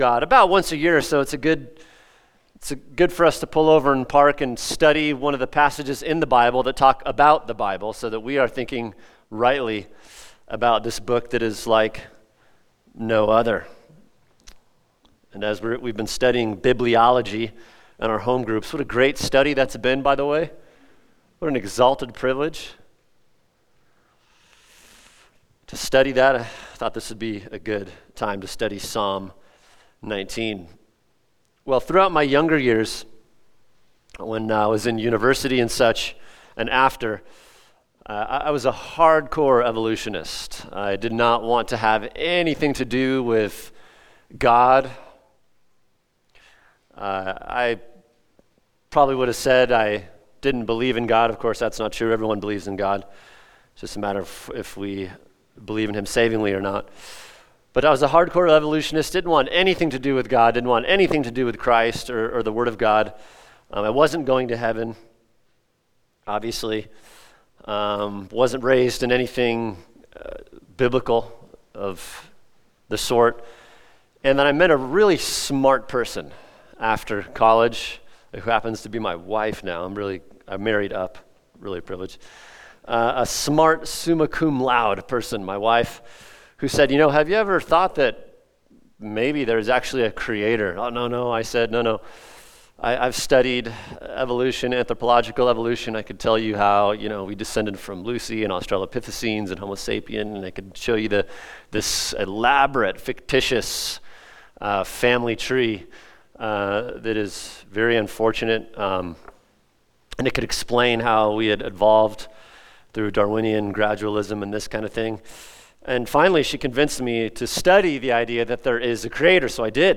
[sermon] Psalm 19 The Power and Sufficiency of the Bible | Cornerstone Church - Jackson Hole